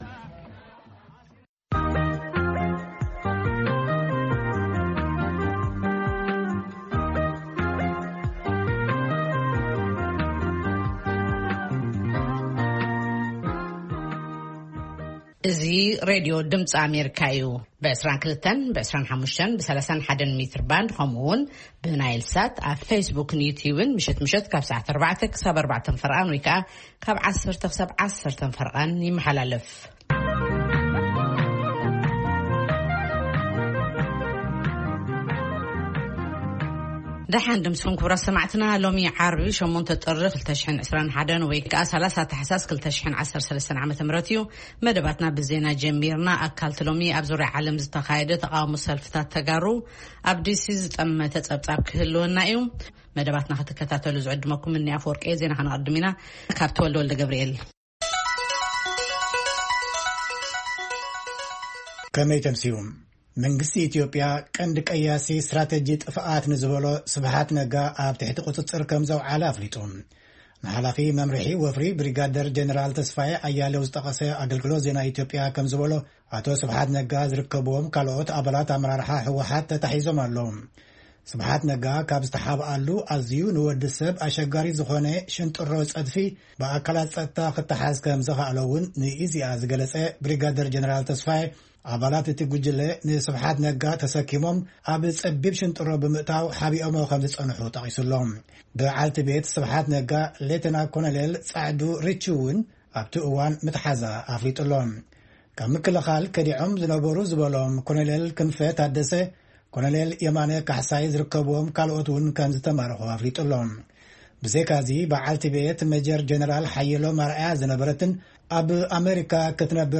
ፈነወ ትግርኛ ብናይ`ዚ መዓልቲ ዓበይቲ ዜና ይጅምር ። ካብ ኤርትራን ኢትዮጵያን ዝረኽቦም ቃለ-መጠይቓትን ሰሙናዊ መደባትን ድማ የስዕብ ። ሰሙናዊ መደባት ዓርቢ፡ ቂሔ-ጽልሚ / ፍሉይ መደብ/ ሕቶን መልስን